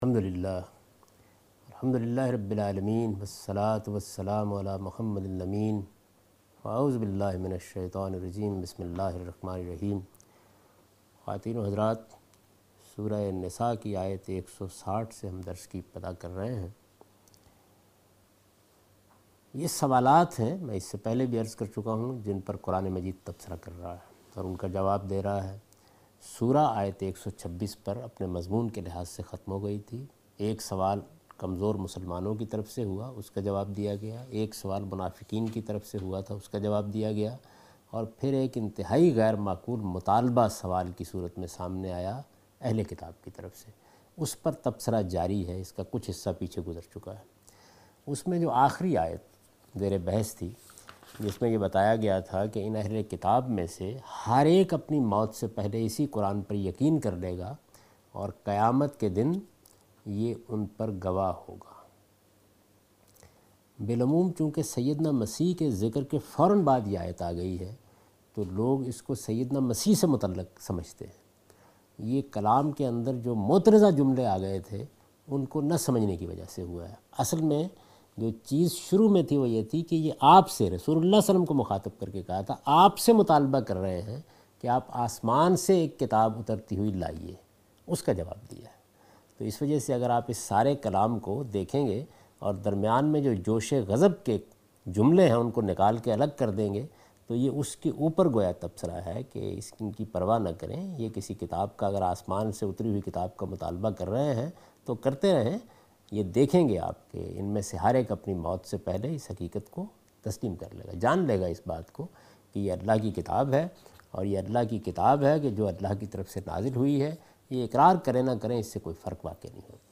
Surah Al-Nisa - A Lecture of Tafseer ul Quran Al-Bayan by Javed Ahmed Ghamidi